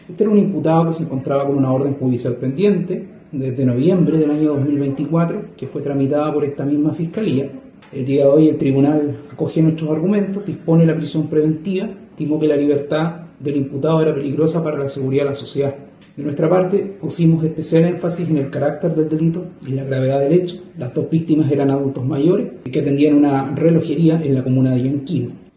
Al respecto, el fiscal subrogante de Puerto Varas, Marco Antimilla, dijo que afirmó sus argumentos para la formalización en la gravedad del hecho.